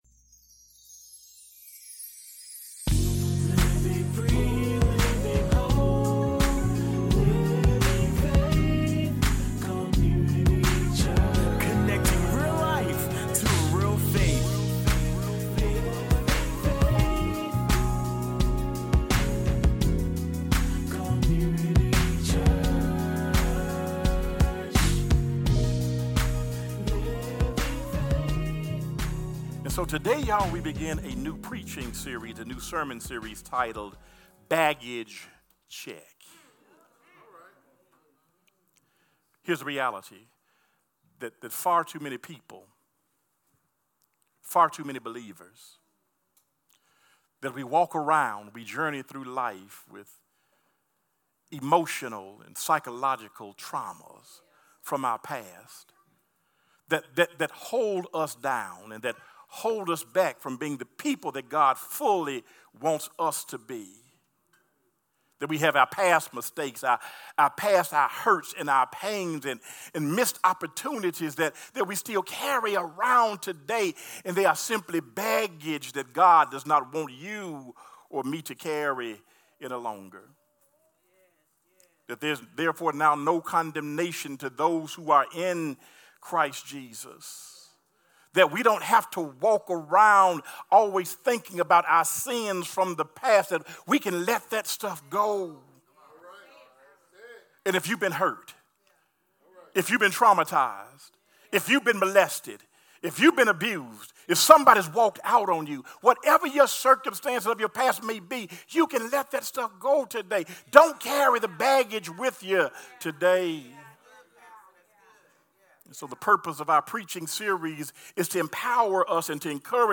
Sermons | Living Faith Community Church